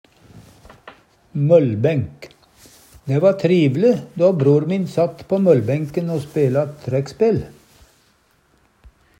møllbenk - Numedalsmål (en-US)
DIALEKTORD